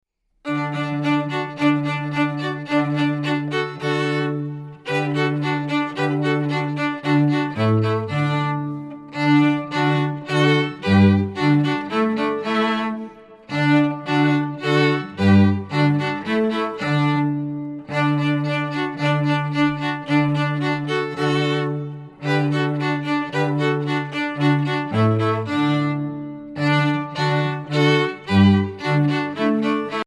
Voicing: Cello and Online Audio